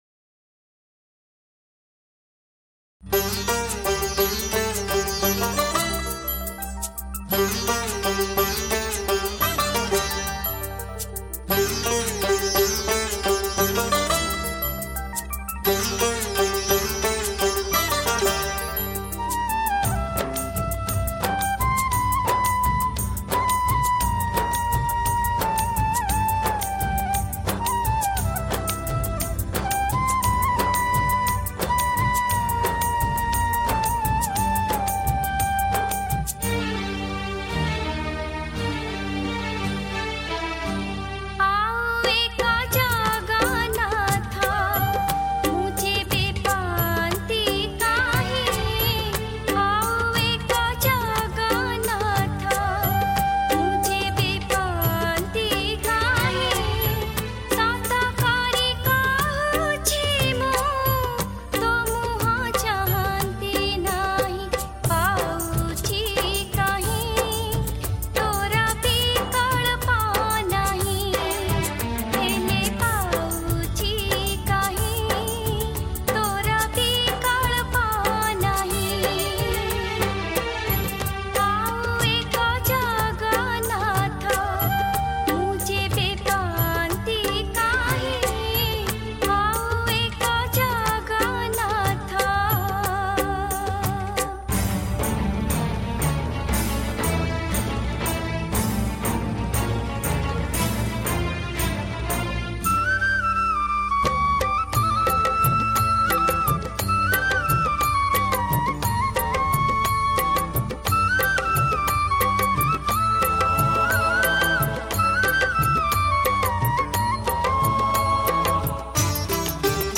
Jagannath Bhajan